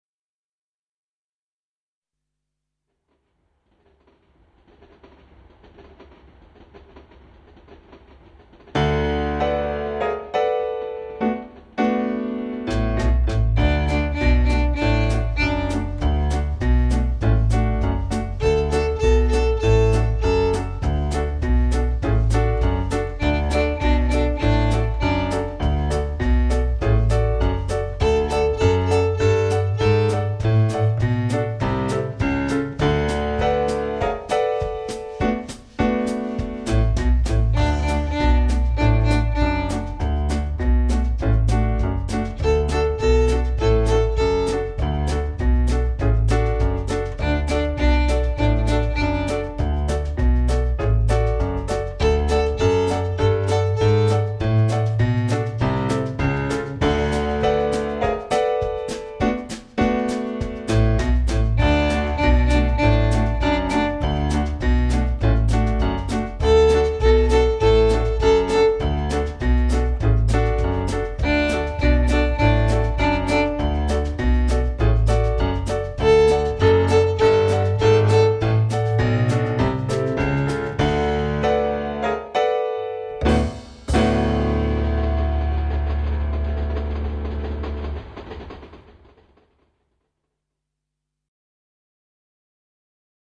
19 Rhythm Train (Violin Backing Track)